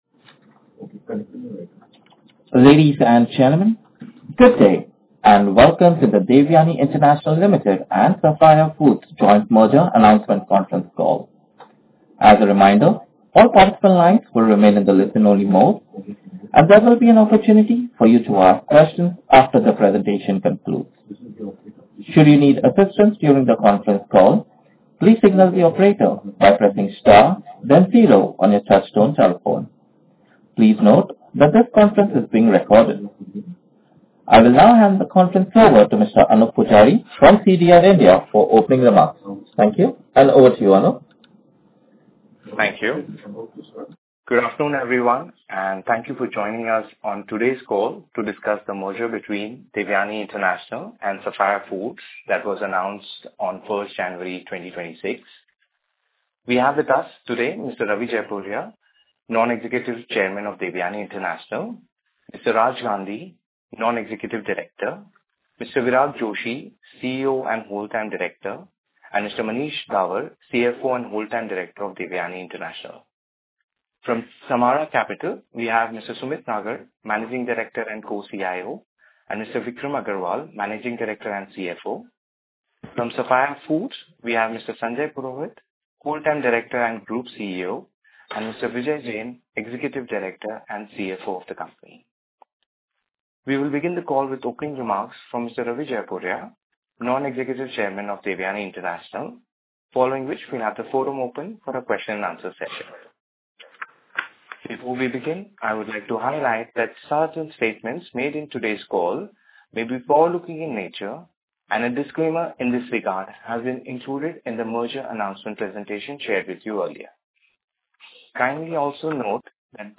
dil-sfil-announcement-concall-audio.mp3